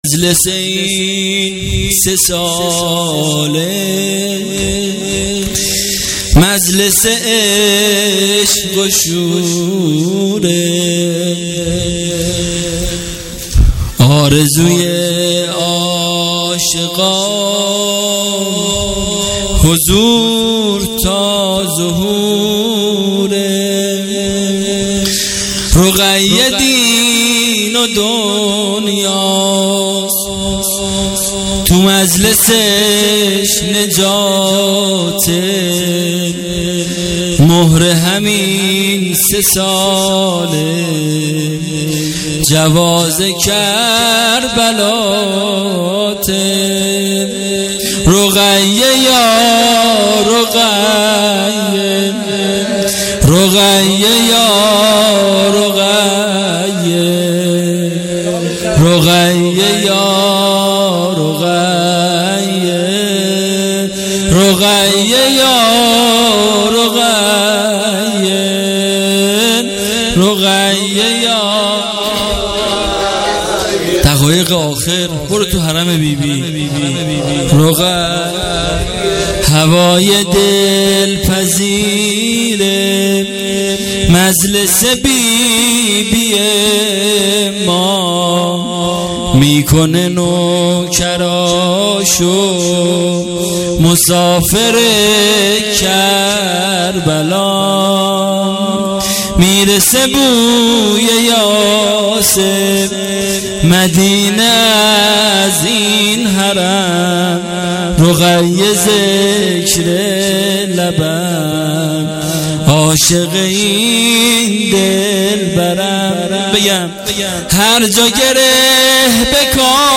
جشن میلادحضرت رقیه (س)99